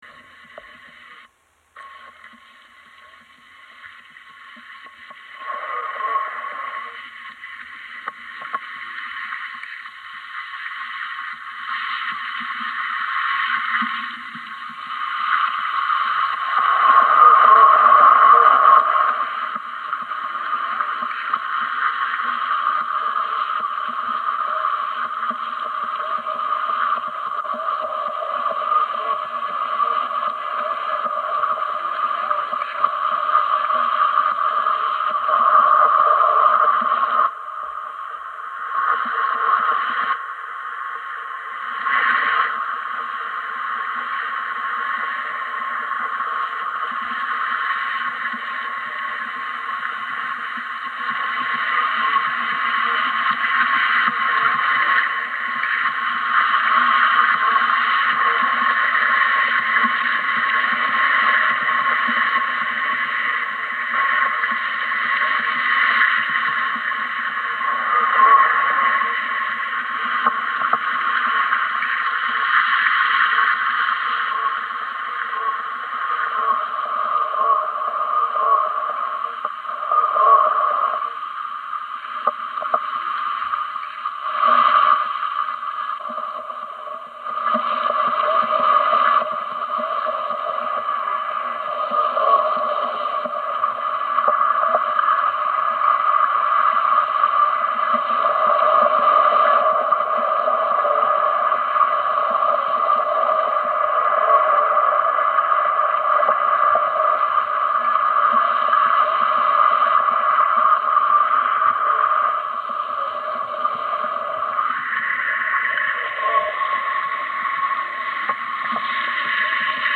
Bayaka group water drumming reimagined